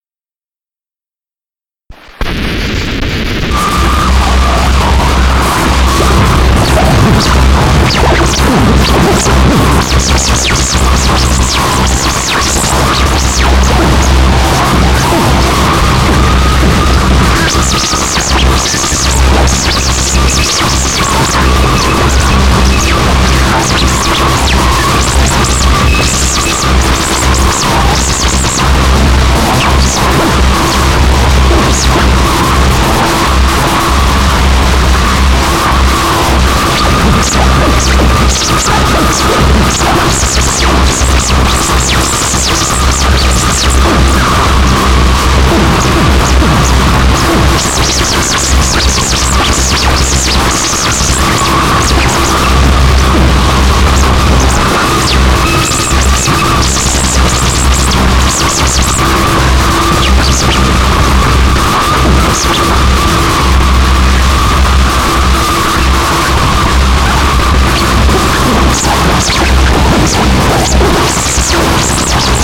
Digitally transferred in 2024 from the original tapes.
Other instruments used were guitar,
rhythm box and many other noise generators.